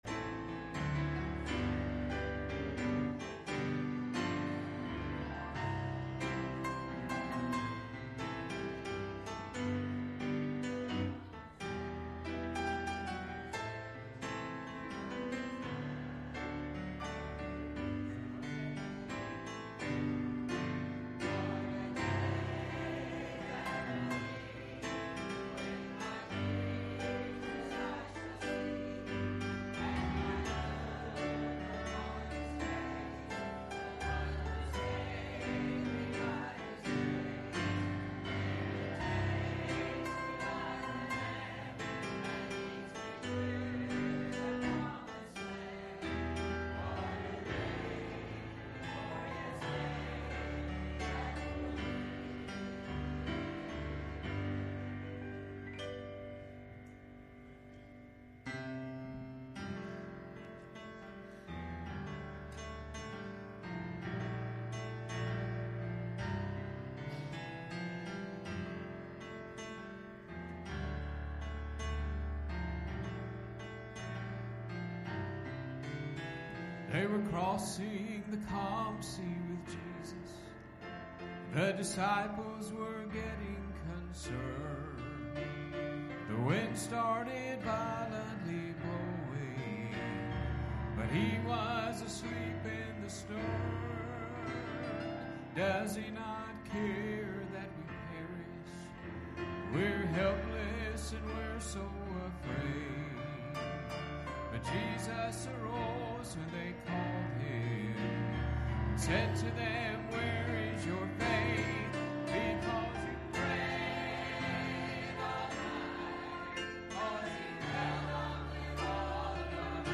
Mark 2:1-12 Service Type: Sunday Evening « Do You Know About New ?